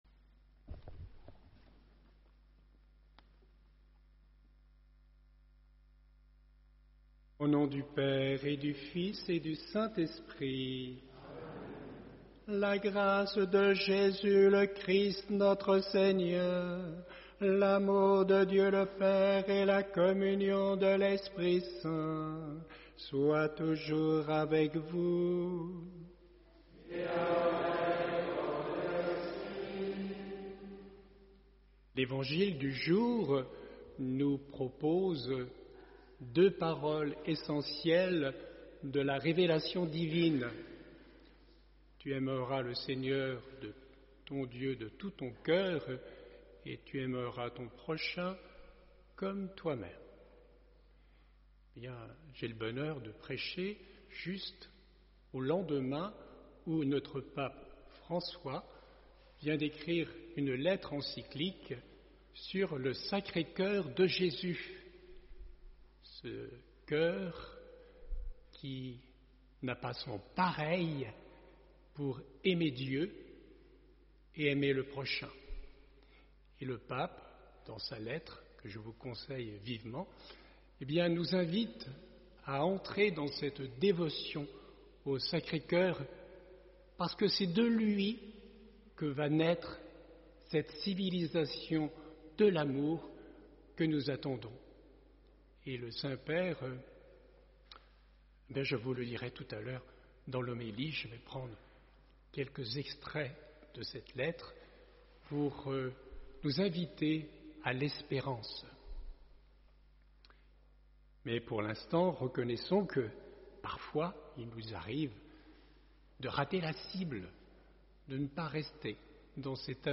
Homélie : Comme je vous l’annonçais au début de cette célébration, je vais orienter le petit mot de ce matin sur cette lettre que nous laisse notre pape François à méditer.